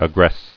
[ag·gress]